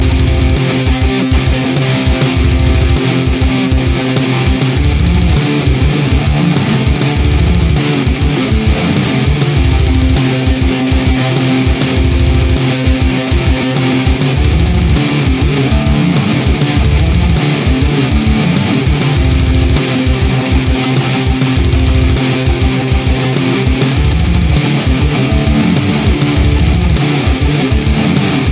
Alternative Electric Guitar Riff.